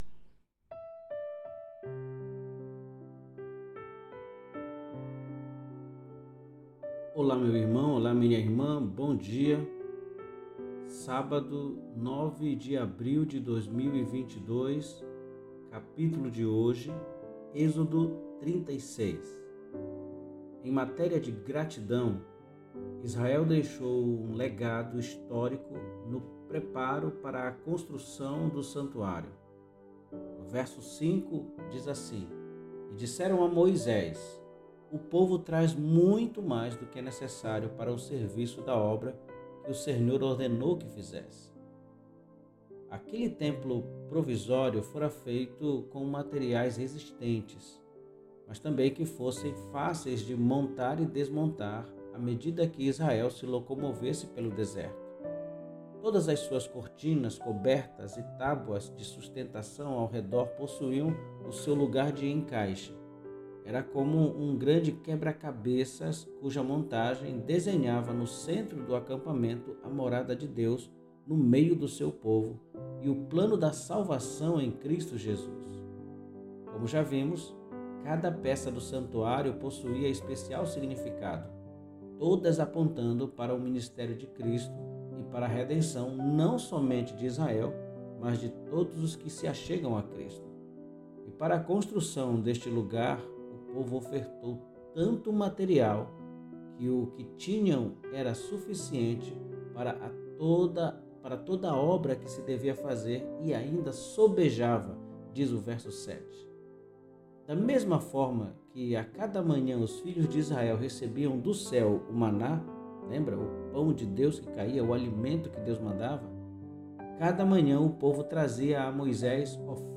PODCAST DE MEDITAÇÃO BÍBLICA